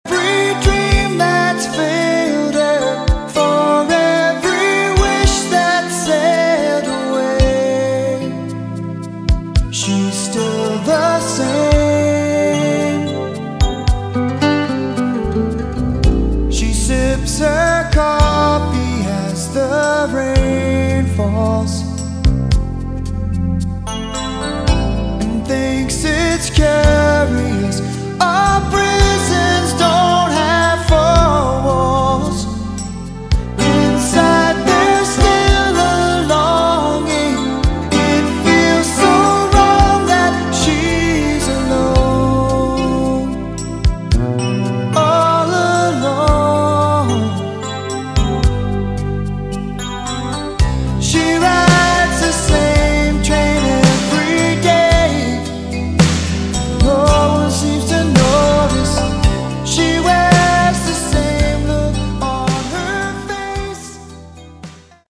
Category: Westcoast AOR